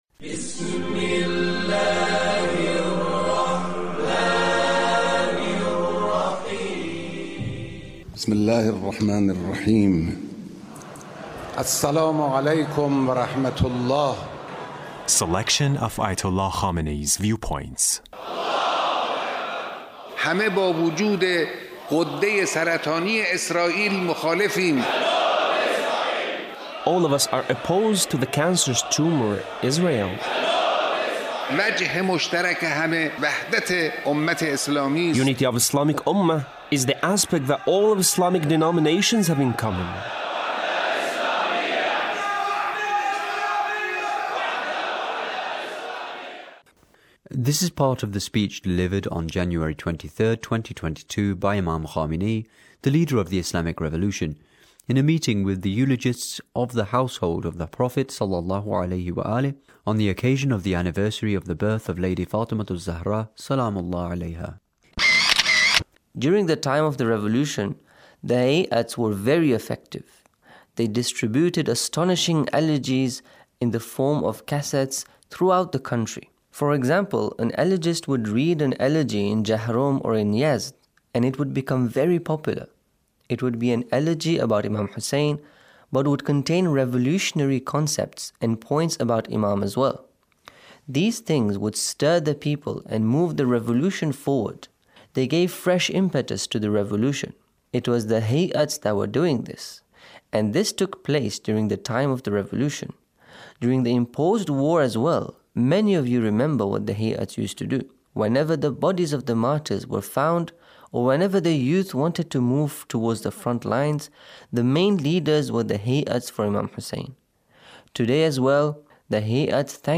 Leader's Speech on a Gathering with eulogists of the Household of the Prophet